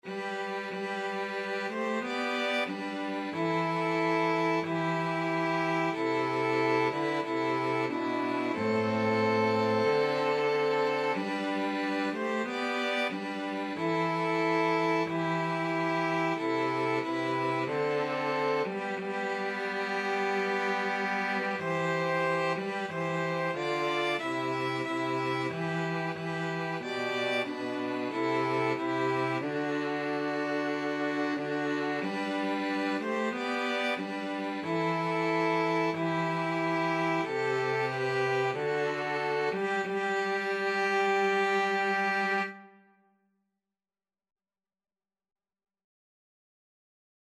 4/4 (View more 4/4 Music)
Andante = c. 92
2-Violins-Cello  (View more Easy 2-Violins-Cello Music)
Classical (View more Classical 2-Violins-Cello Music)